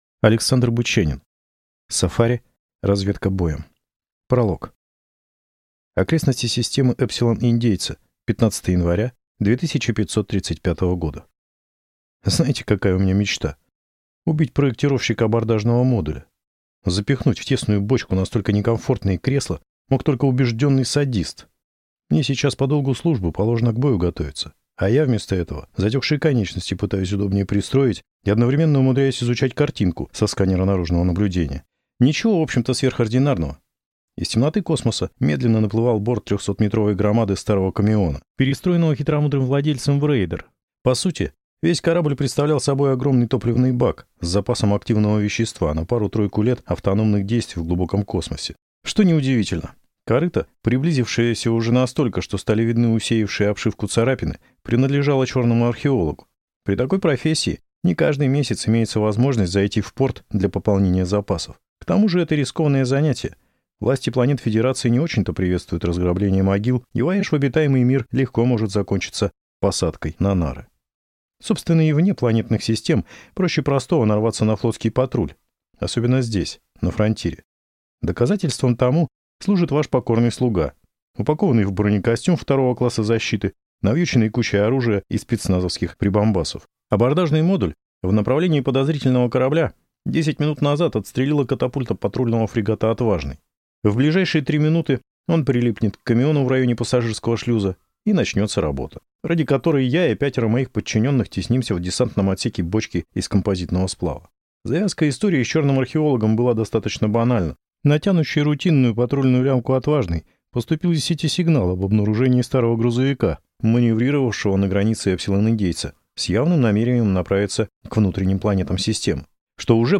Аудиокнига Сафари. Разведка боем | Библиотека аудиокниг